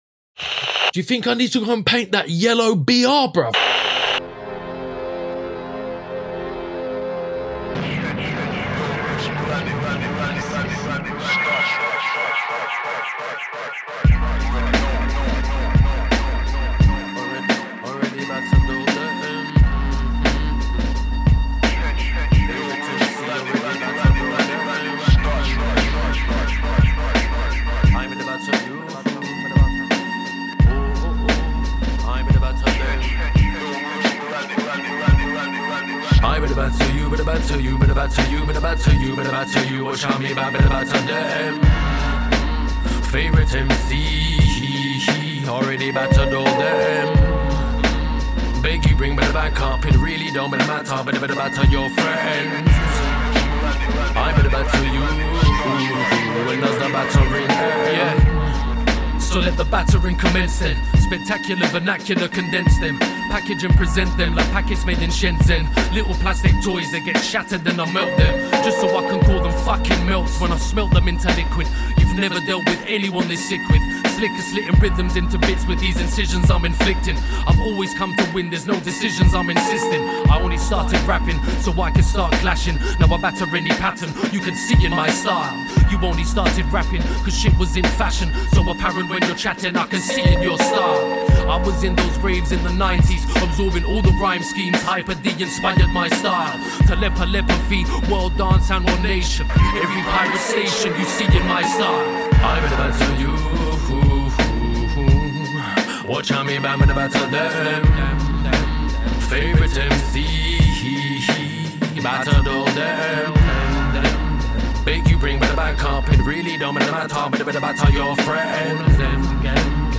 This week the lads are on a, very tall, location... Lots of Graff chat in this one and lots of flicks, music and Graff Critique...